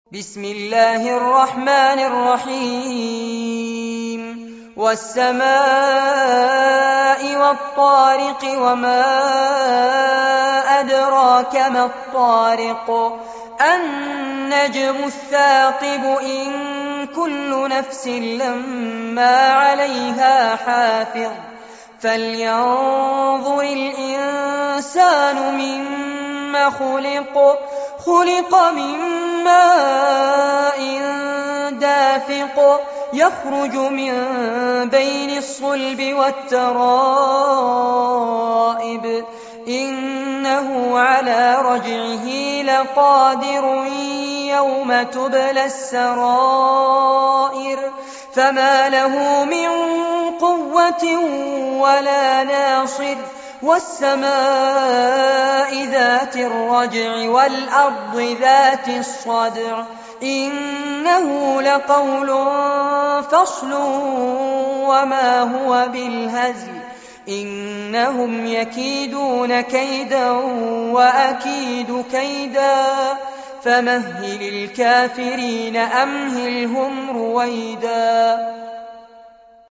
سورة الطارق- المصحف المرتل كاملاً لفضيلة الشيخ فارس عباد جودة عالية - قسم أغســـــل قلــــبك 2